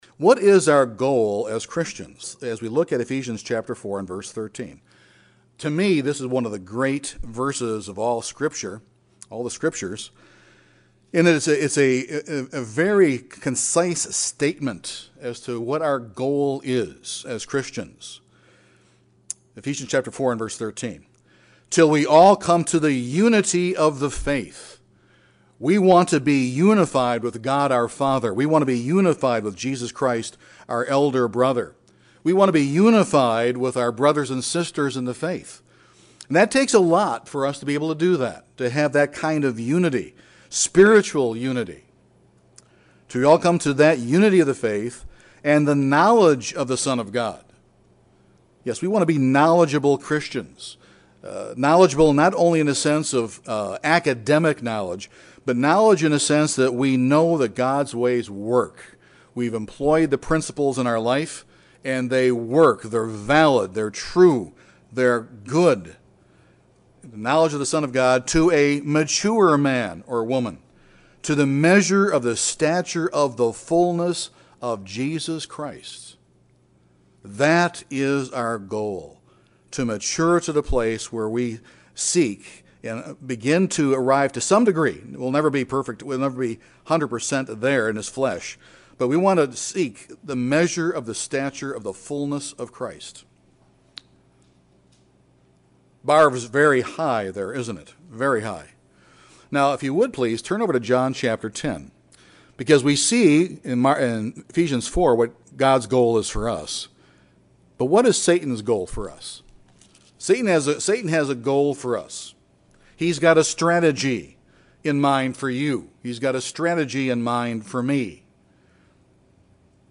How do we, as Christians, grow in spiritual maturity in a world increasingly unstable spiritually? This sermon examines what Jesus Christ did and what we must do to follow His lead.